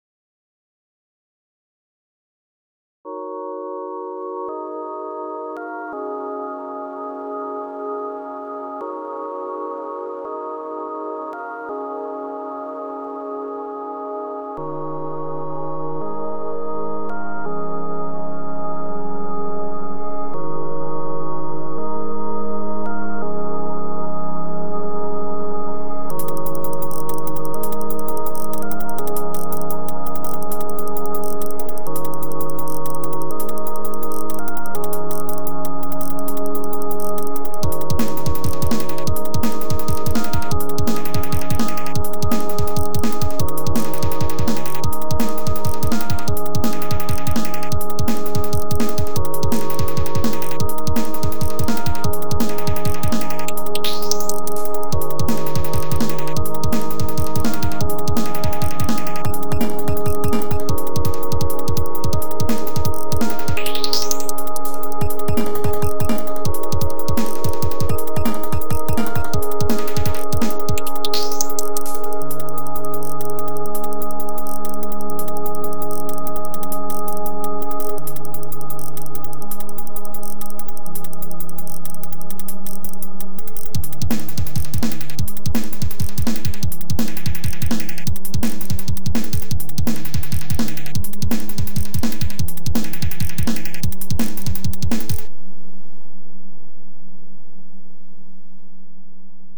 Made with Jeskola Buzz on a Pentium 233Mhz MMX PC with a Sound Blaster AWE32 around 2001, I guess